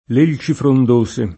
l %l©i frond1Se] (Tasso); Qualch’elce nera [kUalk %l©e n%ra] (Pascoli) — meno com., nell’una o nell’altra forma, l’uso come s. m.: i folti rami del grande elce del parco [